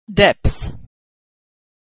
Vowel - Practice - Irreg 1 Syllable Verbs - Authentic American Pronunciation
asthma ... 3 consonant sounds at the end of a word, middle 'th' is silent clothes months depths lengths
ending-depths.mp3